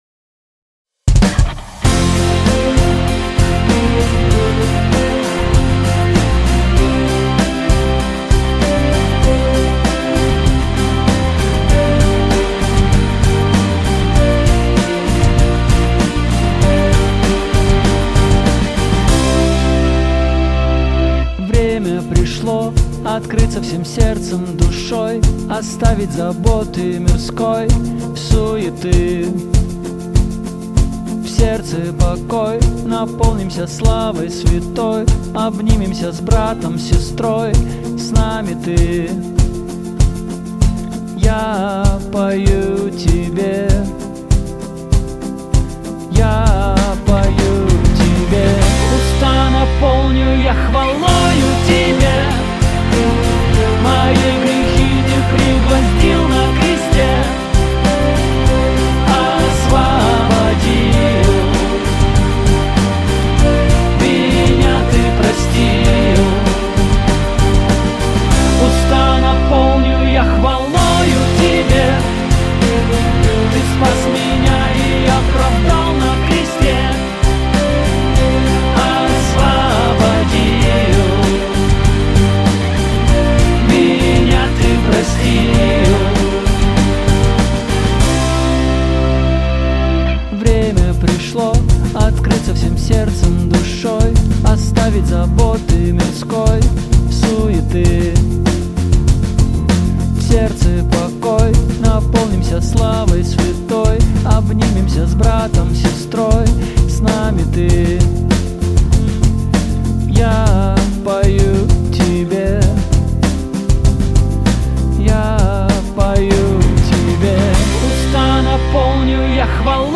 песня
245 просмотров 321 прослушиваний 22 скачивания BPM: 195